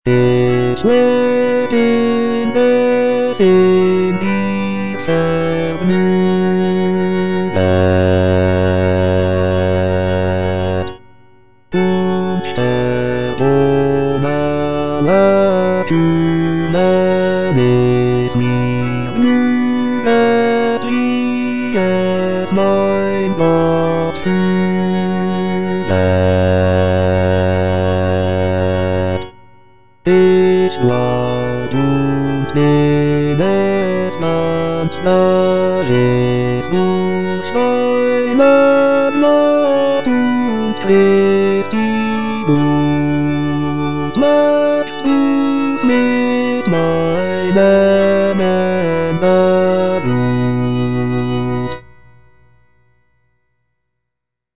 Cantata
Bass (Voice with metronome)